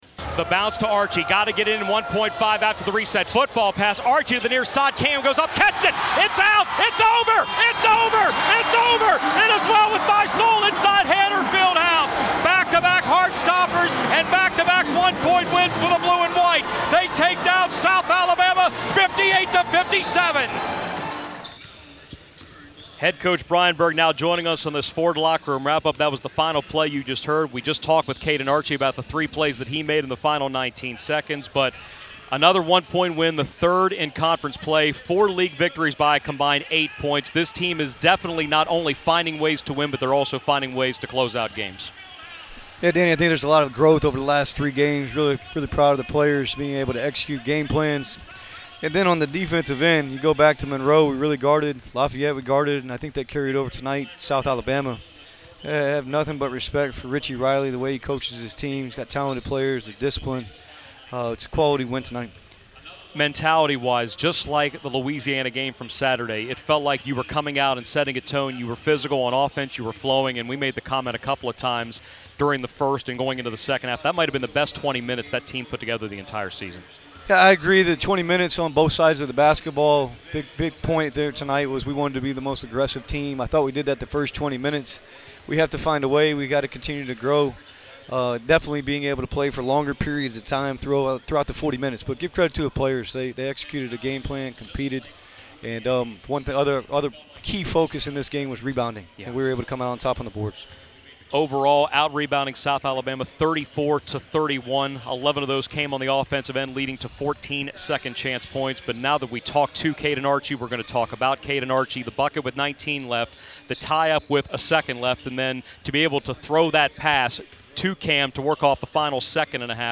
Postgame radio interview